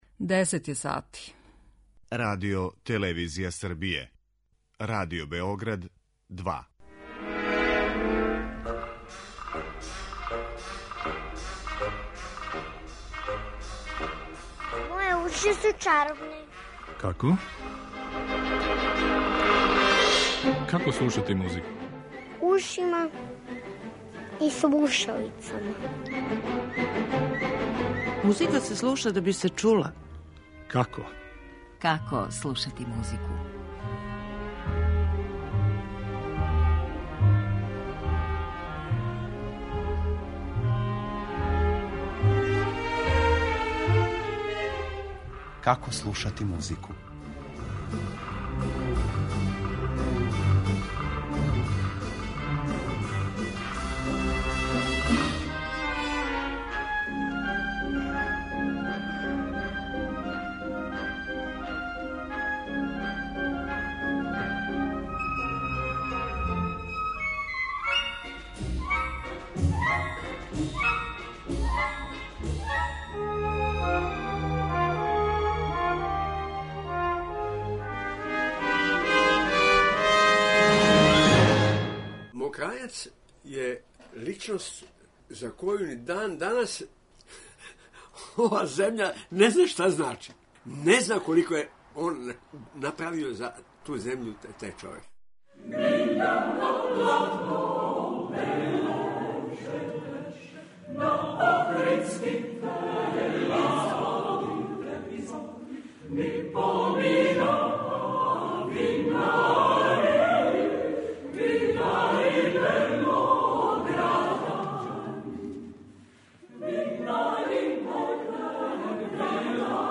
Руковети ћемо анализирати на примеру Јагуштових антологијских интерпретација са Хором РТС-а, снимљених на „Мокрањчевим данима" у Неготину.